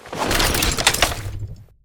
holster.ogg